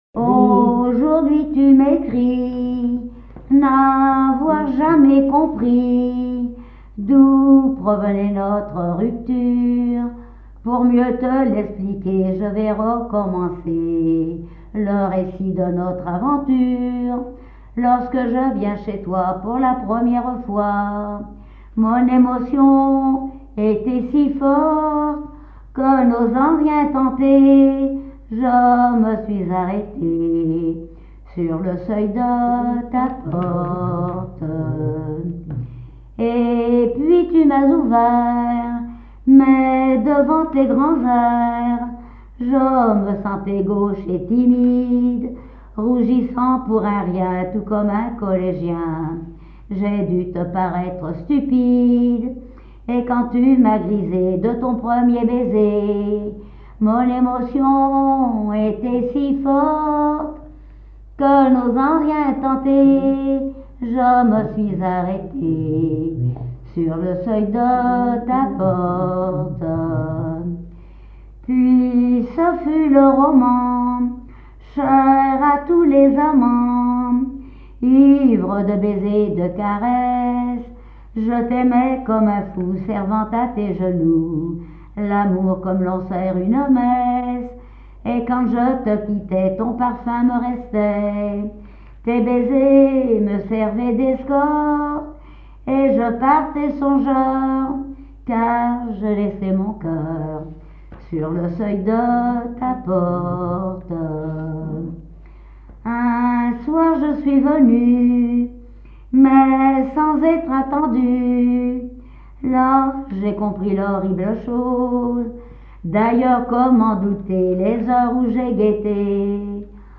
Genre strophique
témoignages sur le poissonnerie et chansons
Pièce musicale inédite